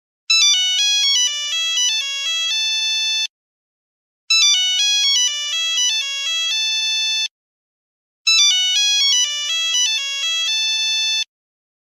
1994 Nokia